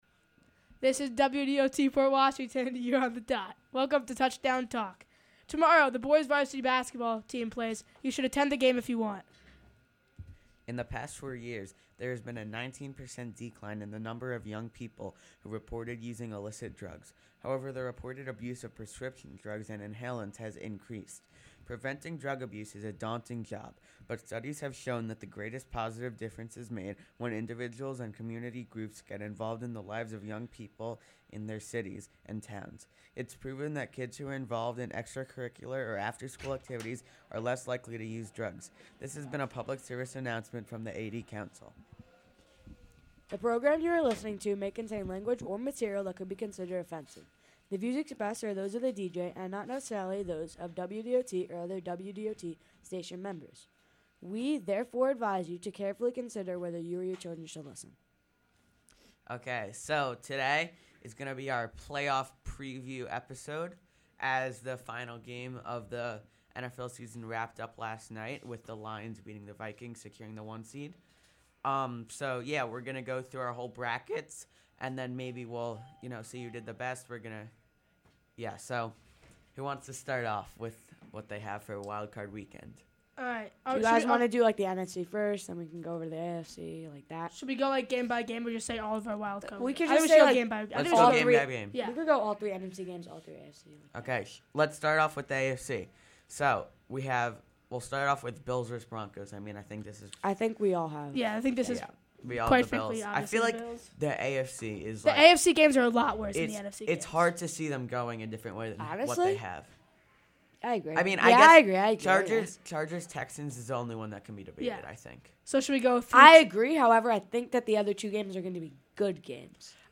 Music used is incidental or background clips, in accordance of 37 CFR 380.2. of the US Copyright Law.